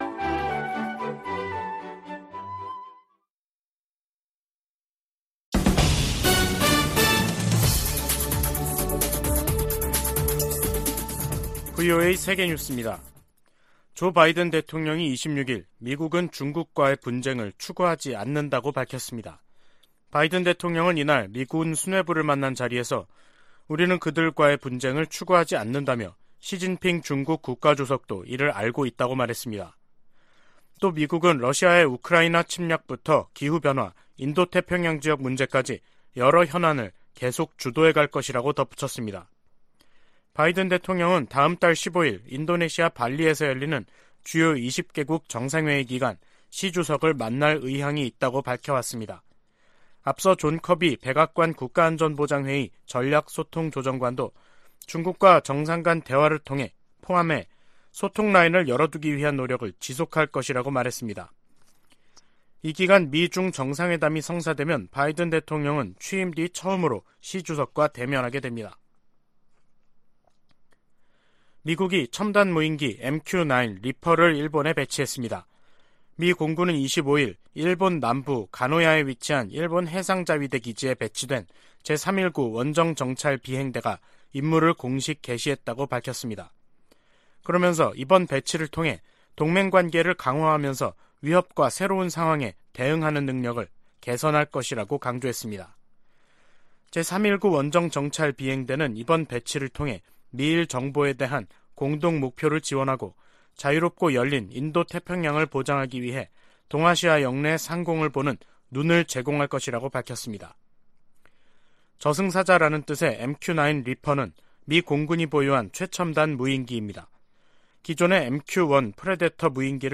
VOA 한국어 간판 뉴스 프로그램 '뉴스 투데이', 2022년 10월 27일 2부 방송입니다. 북한이 7차 핵실험을 감행할 경우 연합훈련과 추가 제재 등 다양한 대응 방안이 있다고 미 국무부가 밝혔습니다. 한국 국가정보원은 북한이 미국의 11월 중간선거 이전까지 7차 핵실험을 할 가능성이 있다는 기존의 정보분석을 거듭 제시했습니다. 2023 회계연도 국방수권법안에 대한 미국 상원 본회의 심의가 시작됐습니다.